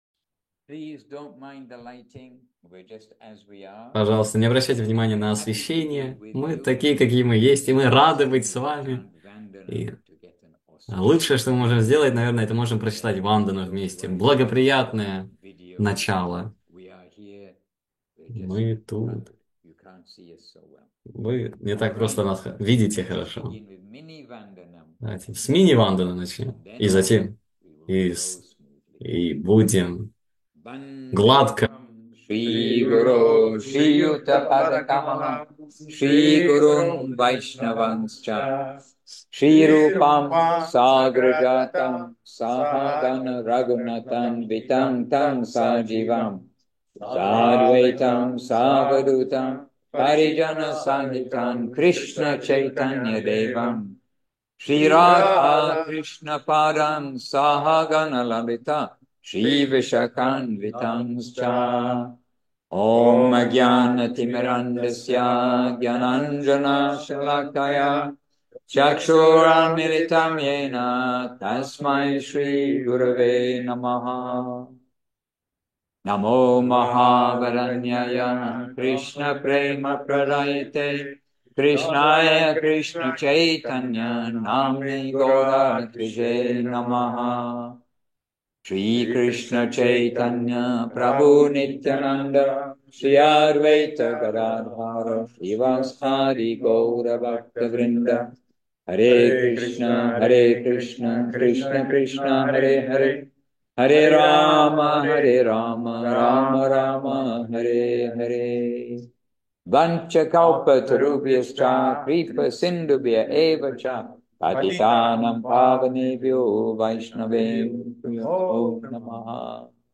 Ферма Говинда, Таиланд
Лекции полностью